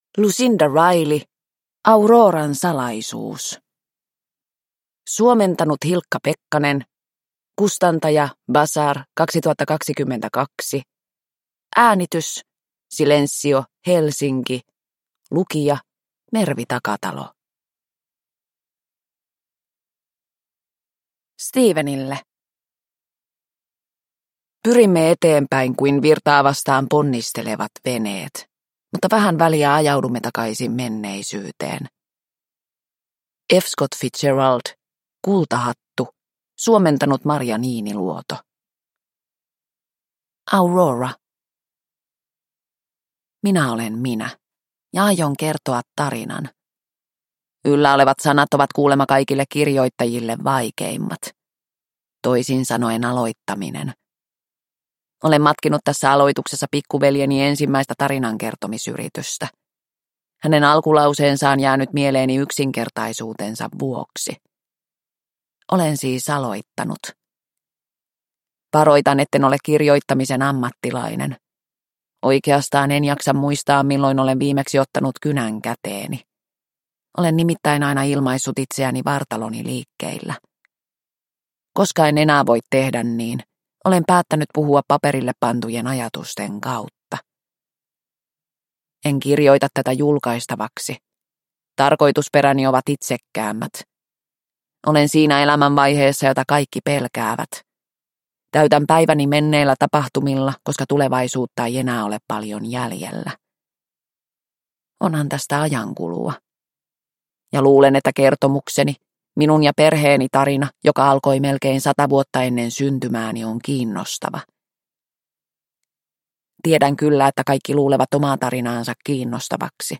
Auroran salaisuus – Ljudbok – Laddas ner